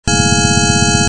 Sonification 4: Drones
Each position is a sine oscillator.
Unfortunately, this sounds super harsh.
Comment: This sounds really harsh, even for a single state.